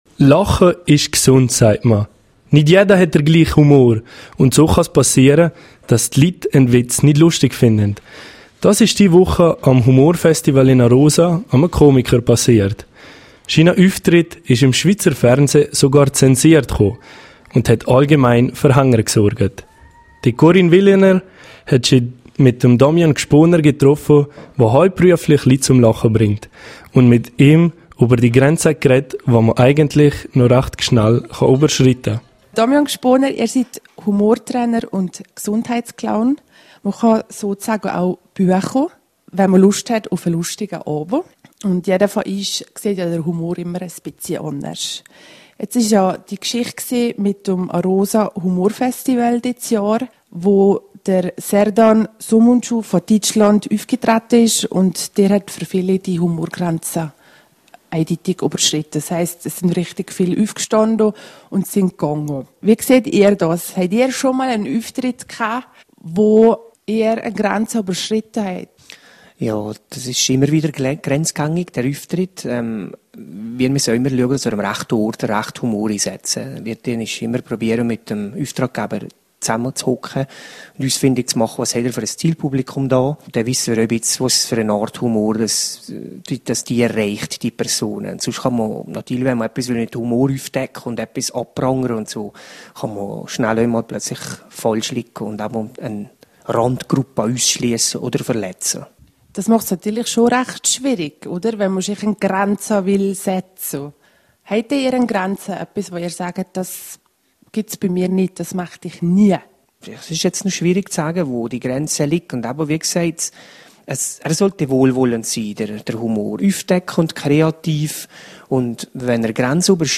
Interview nichts bei!